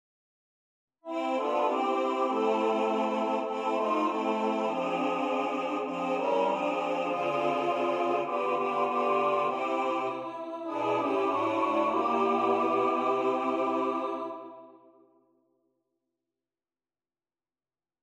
Key written in: C Major
How many parts: 4
Type: SATB
All Parts mix: